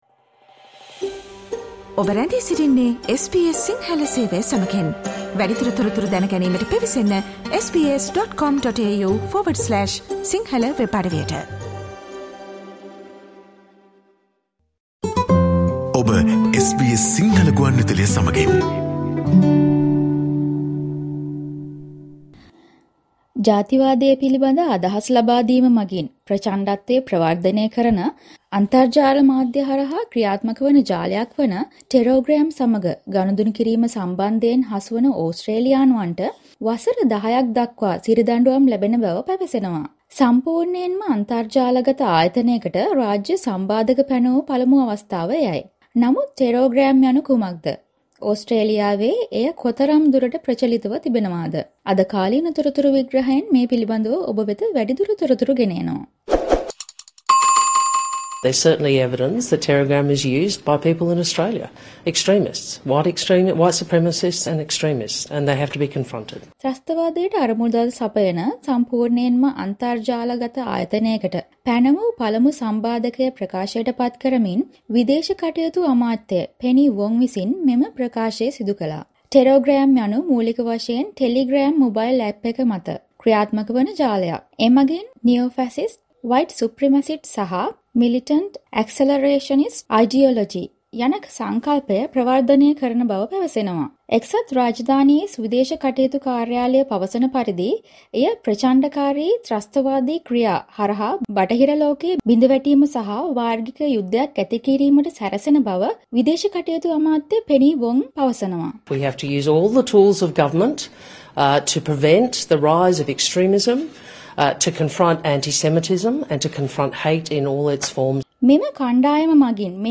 Australians caught dealing with Terrorgram, an online network that promotes racially motivated violence, will now face up to 10 years in prison. It’s the first time the government has imposed sanctions on an entirely online entity. Listen to SBS Sinhala explainer for more information.